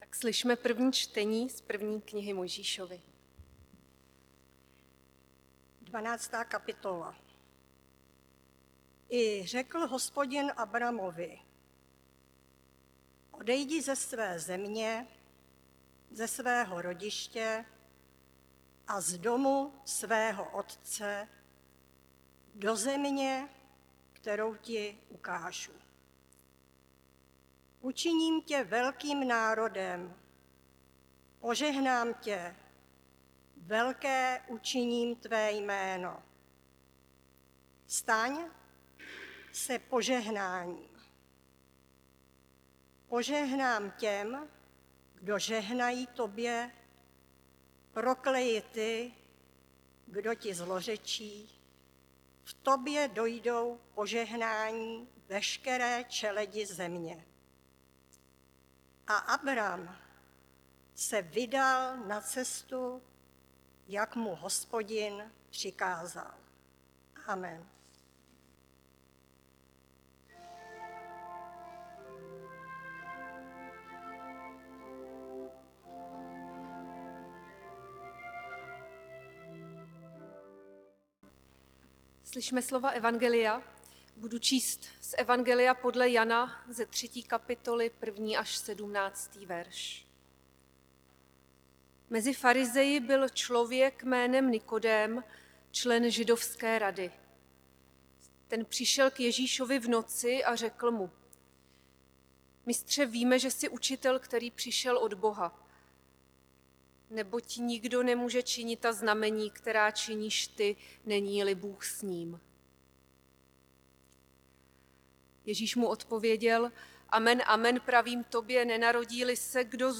záznam kázání